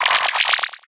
Cri de Wattapik dans Pokémon HOME.